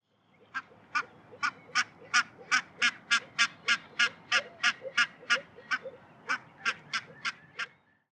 Brandgans
De roep van de brandgans klinkt hoog en schel.